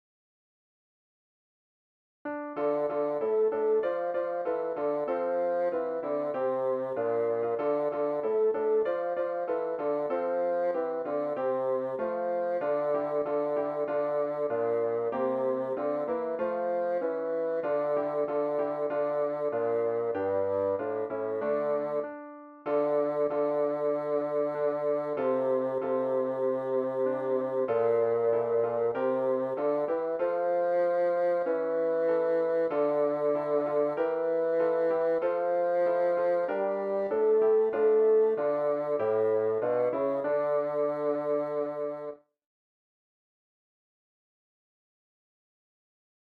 Traditionnel Ashkénaze